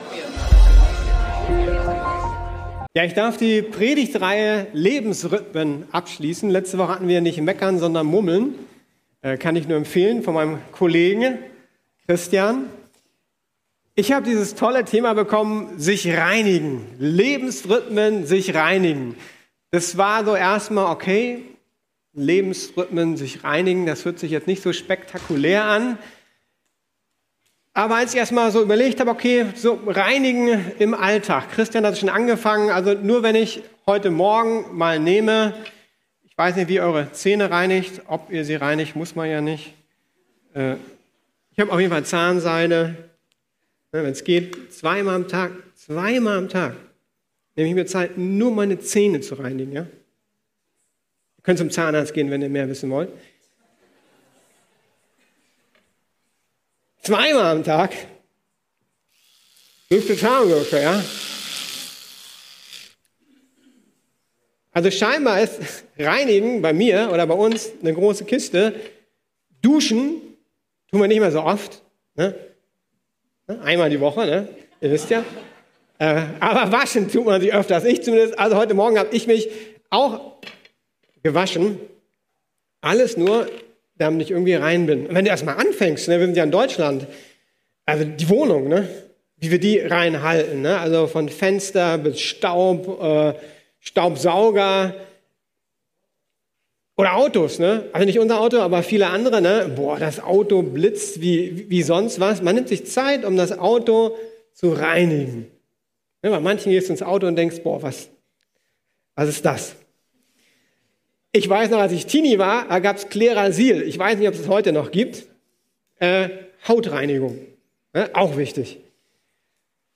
Lebensrhythmen: Sich reinigen ~ Predigten der LUKAS GEMEINDE Podcast